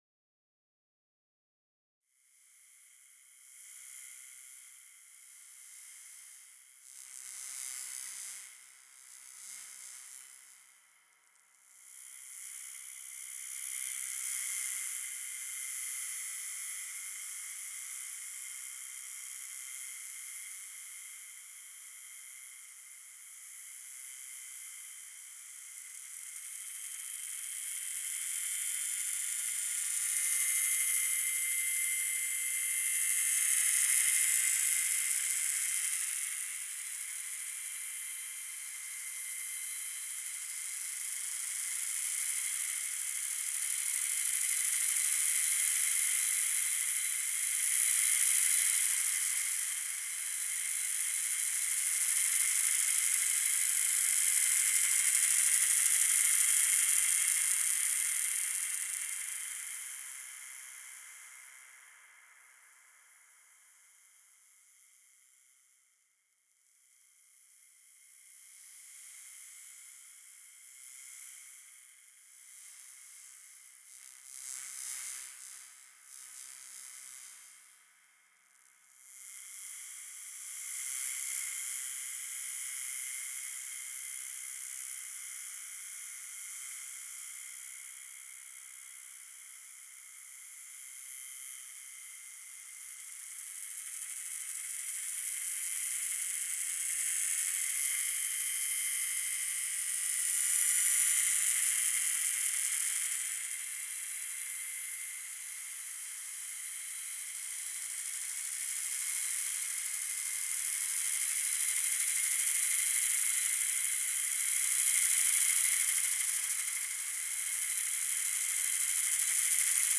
Field Recording Series
A fine electro-acoustic work